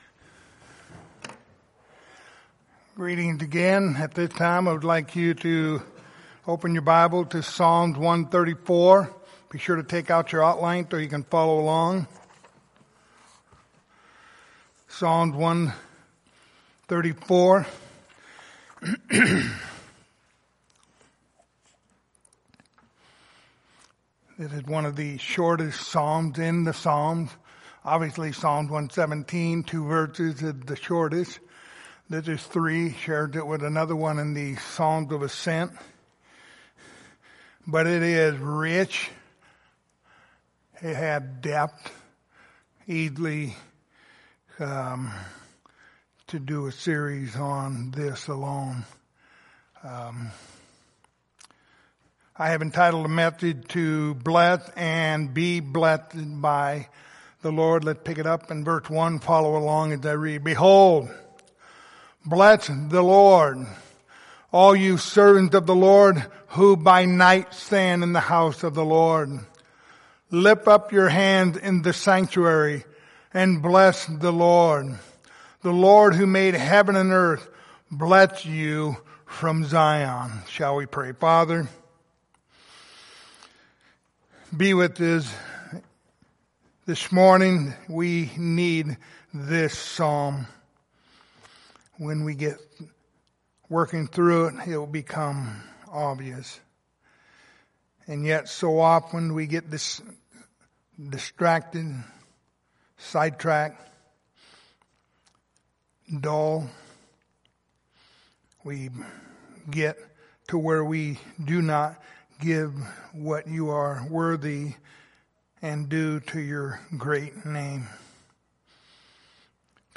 Passage: Psalm 134:1-3 Service Type: Sunday Morning